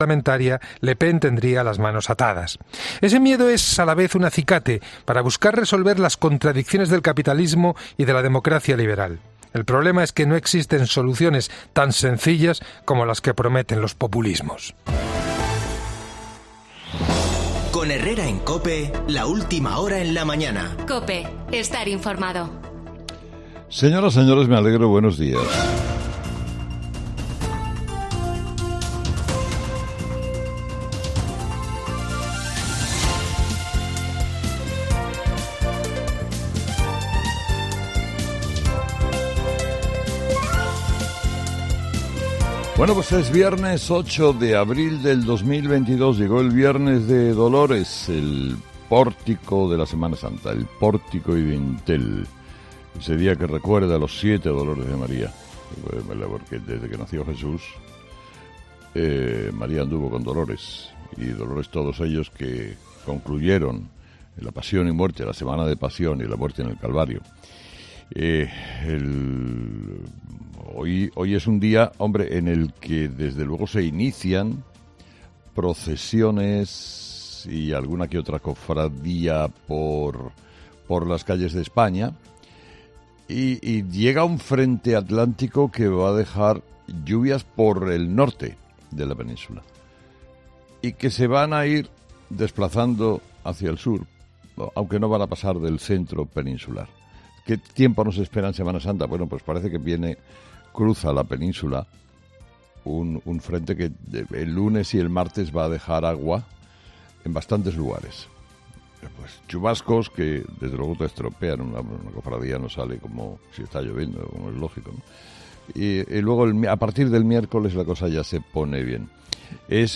Carlos Herrera, director y presentador de 'Herrera en COPE' ha comenzado el programa de este viernes analizando las principales claves de la jornada, que pasan, entre otros asuntos, por los encuentros que Pedro Sánchez ha mantenido este pasado jueves, primero con Feijóo en Moncloa y después con Mohamed VI en Rabat.